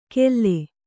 ♪ kelle